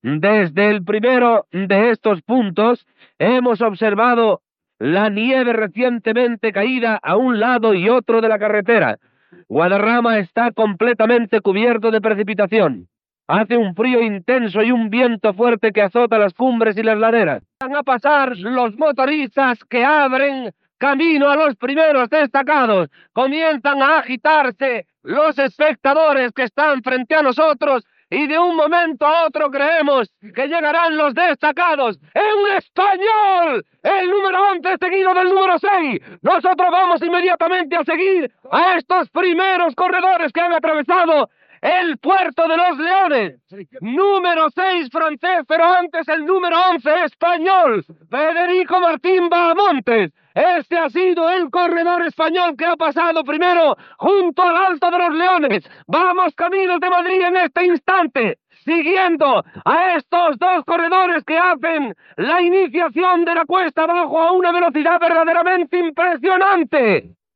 Narració del pas dels primers ciclistes per l'Alto de los Leones, a la Serra del Guadarrama, en la sisena etapa de La Vuelta Ciclista a España entre Valladolid i Madrid
Esportiu